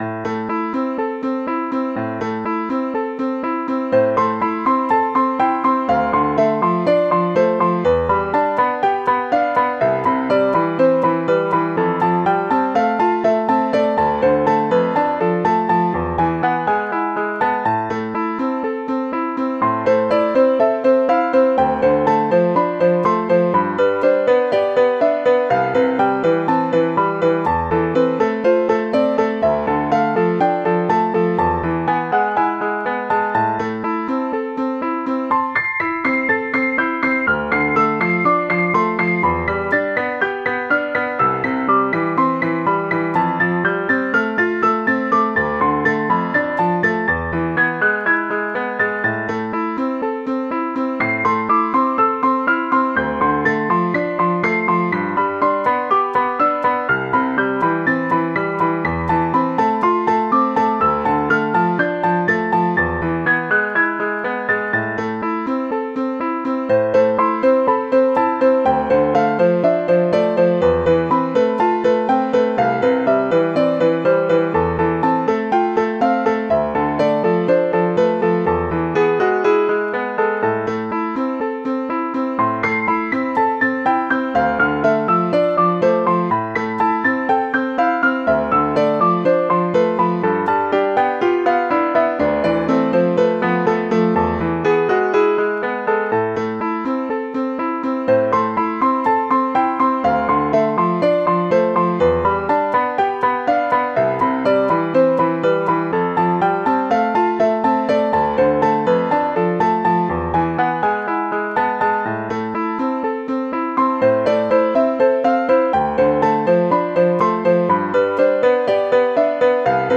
Play demo #1 with piano voice  ▶
passacaglia-handel-halvorsen-piano.mp3